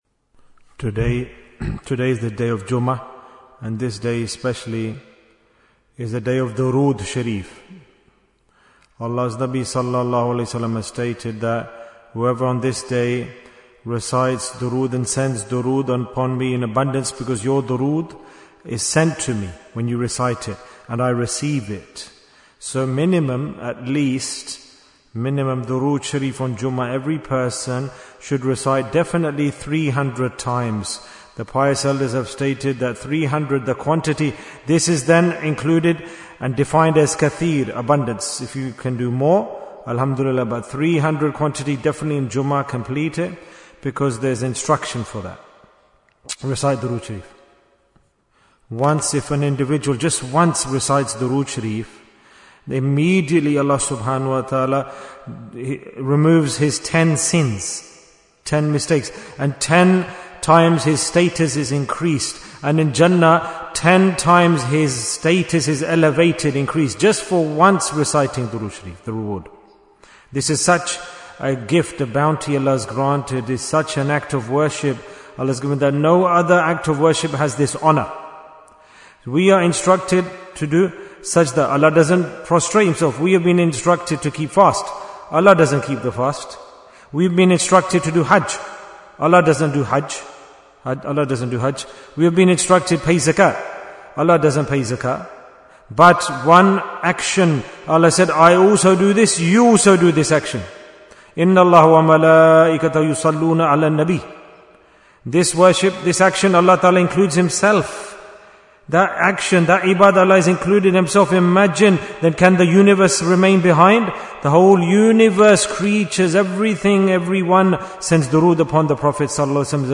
Bayan, 7 minutes